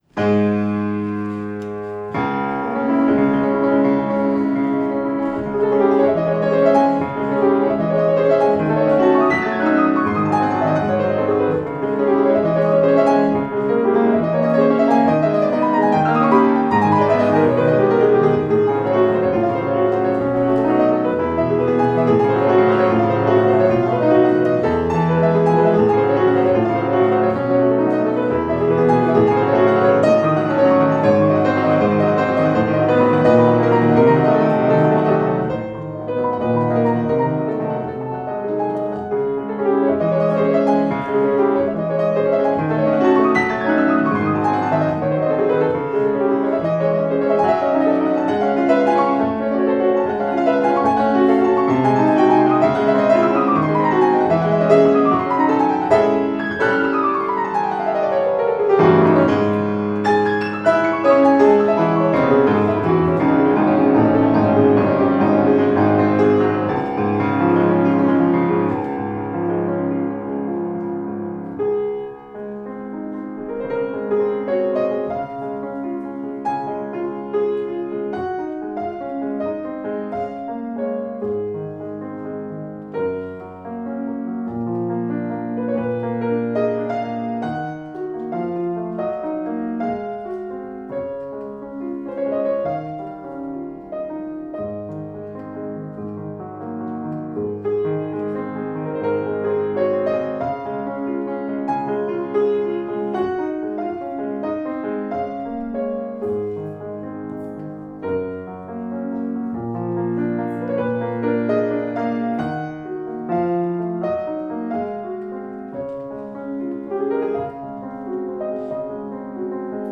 2024-01-13 Concert of Polish Music - Songs and Instrumental Works/ fortepian